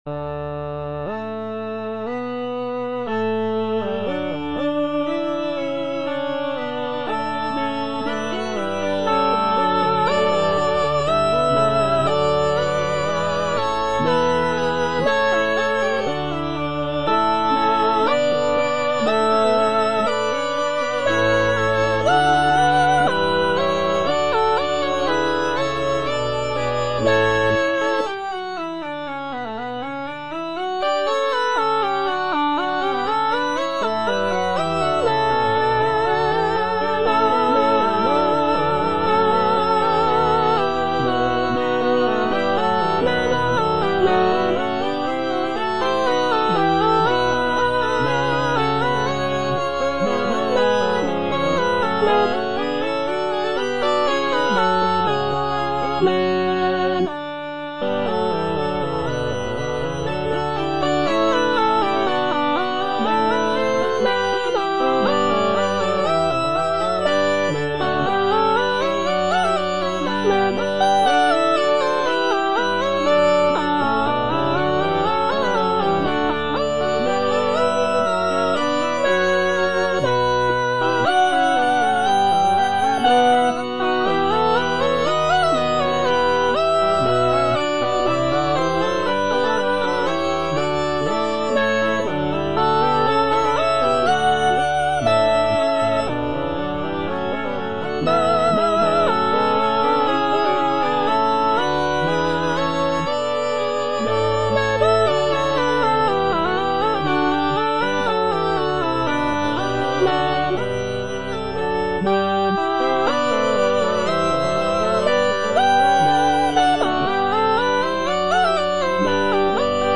J.D. ZELENKA - MAGNIFICAT IN D MAJOR ZWV108 Amen - Soprano (Emphasised voice and other voices) Ads stop: auto-stop
The composition showcases Zelenka's remarkable contrapuntal skills, with intricate vocal lines and rich harmonies.